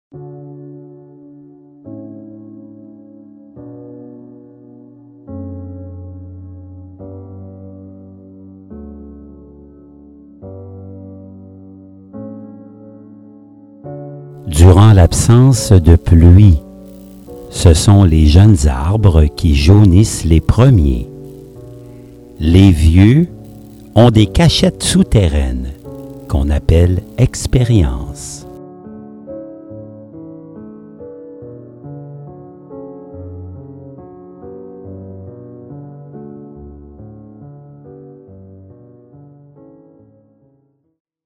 Musique de fond; Le canon de Pachelbel ( violons en 432 htz )
( la qualité sonore est variable… )